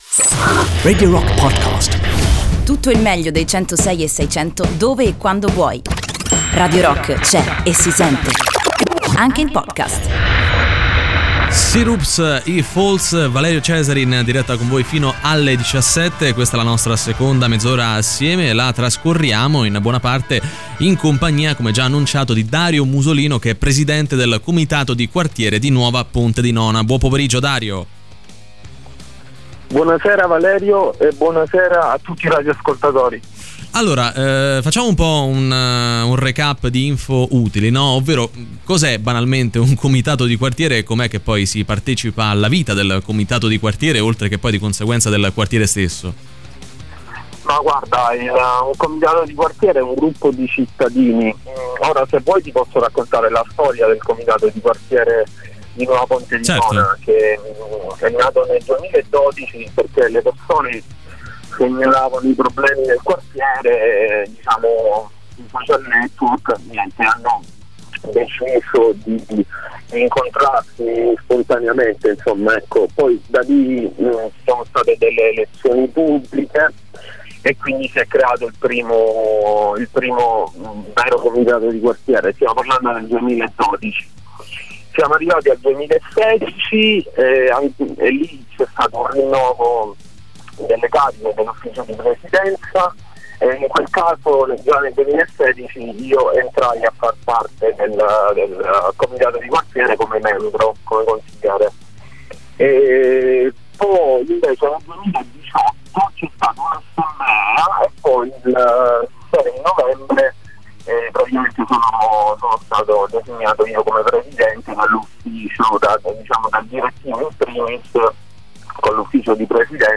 In diretta sui 106.6 di Radio Rock ogni mercoledì a partire dalle 15:30.